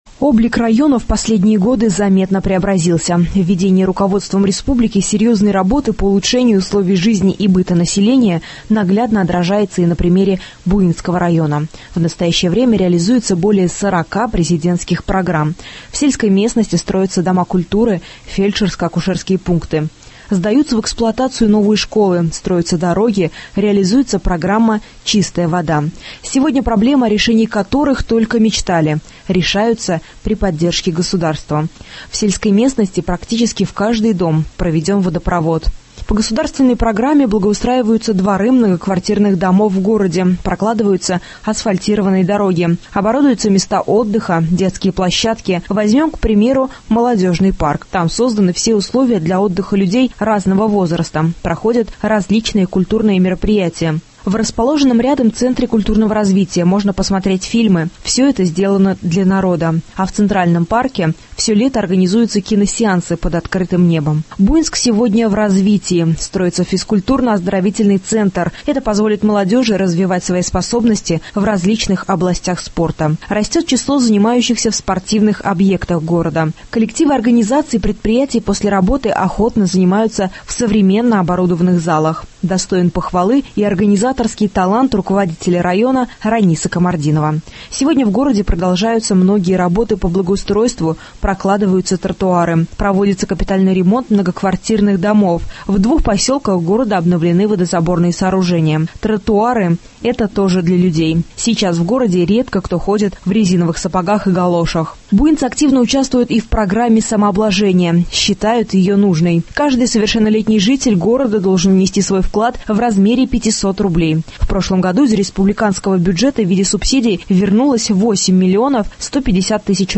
Далее в нашем эфире – репортаж из Буинского района — здесь как и во всех муниципалитетах реализуются десятки республиканских программ.